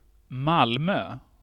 Malmö (/ˈmælmə/ , /-/;[4] Swedish: Malmö [ˈmâlːmøː]
Sv-Malmö.ogg.mp3